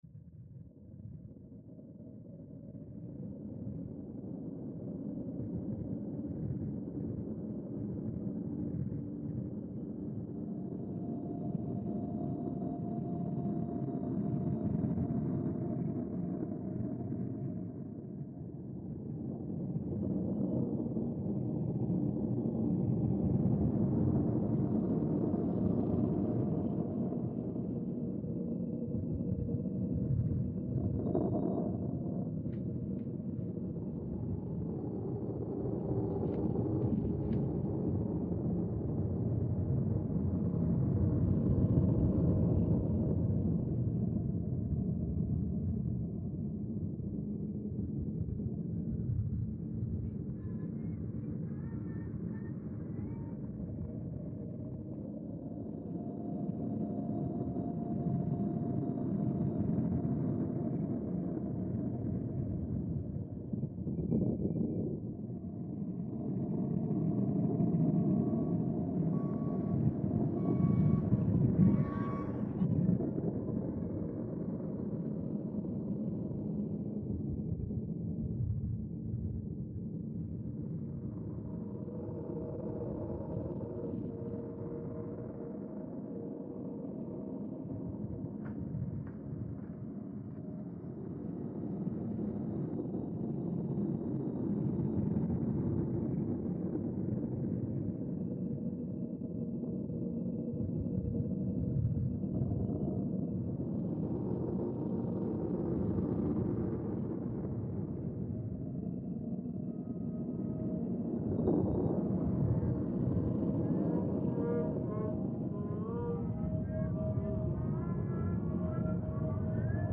I started by selecting and classifying different sections of the recording: wind sounds, trucks beeping, voices singing, and nail/staple guns. In this process, it is sometimes impossible to separate the different sound sources as they overlap and are all contained in a stereo recording.
In many cases it was not perfect, but the imperfections either added a grainy element to the sound, or ended up taking a particular color that was different from the original.